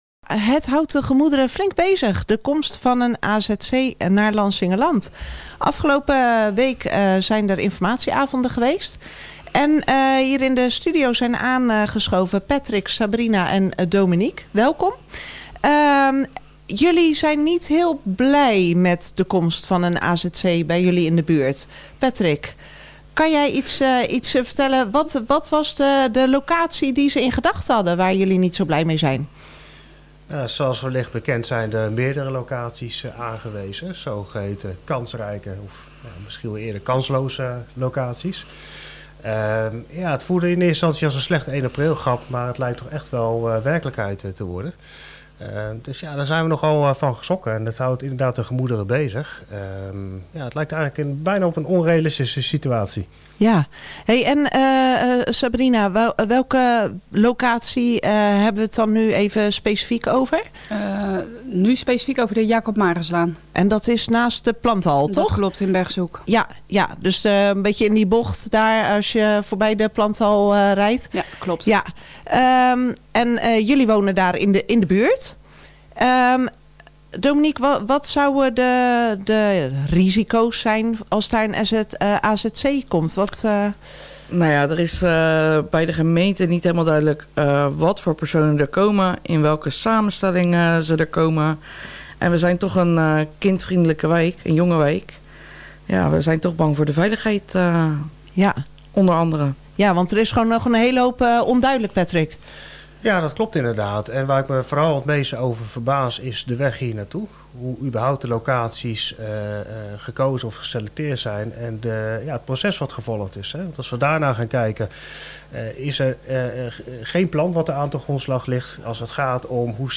Interview-organisatie-demo-tegen-AZC-15-april.mp3